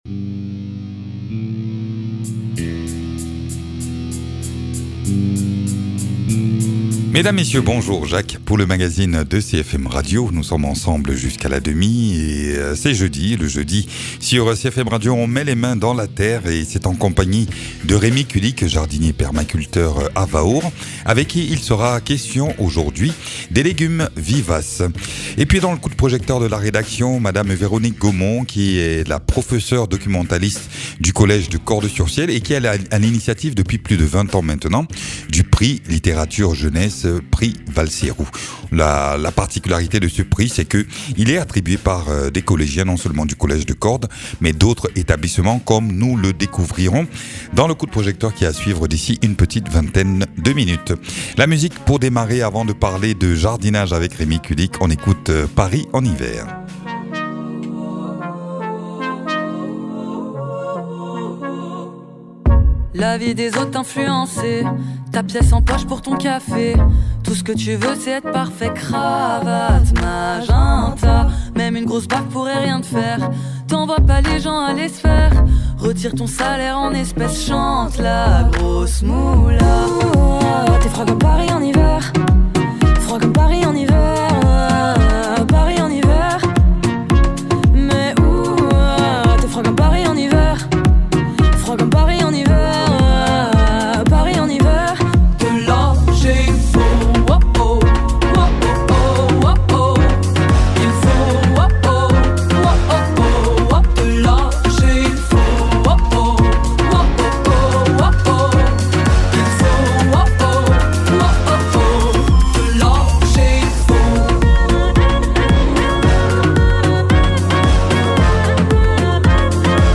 jardinier permaculteur